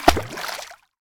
watersplash.ogg